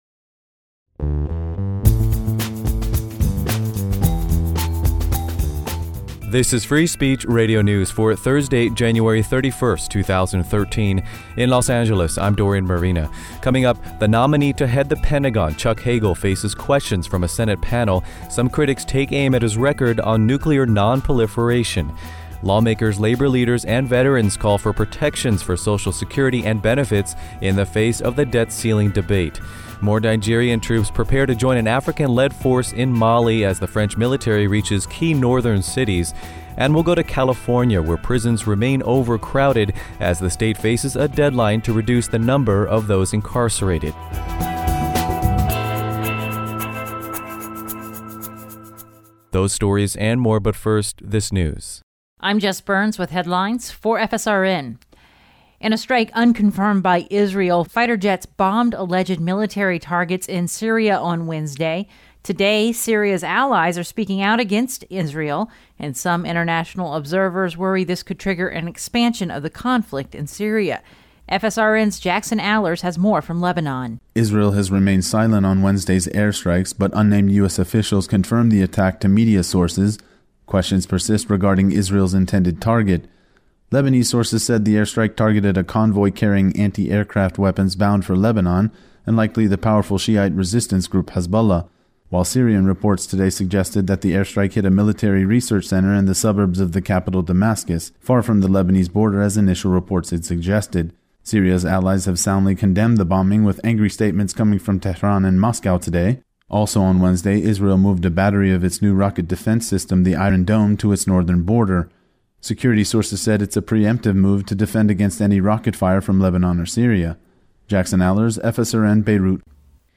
Newscast for Thursday, January 31, 2013